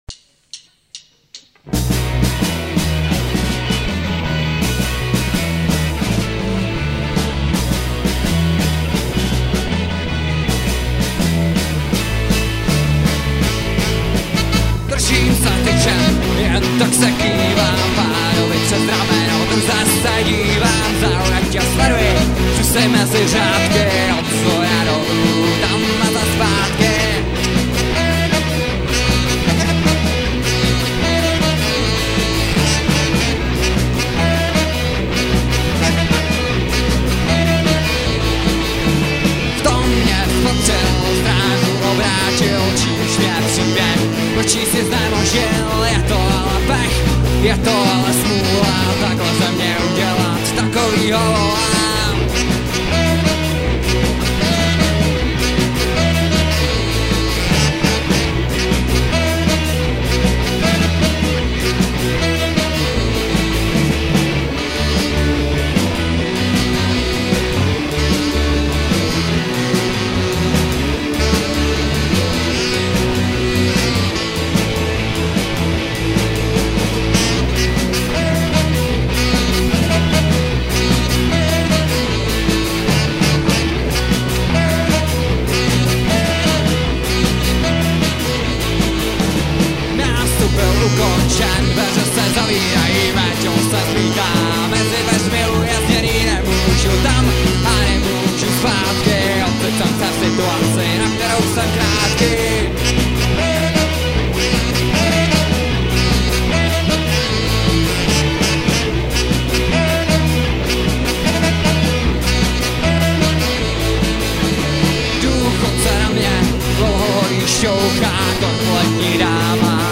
Nahráno živě na koncertu v klubu Prosek dne 22.3.2002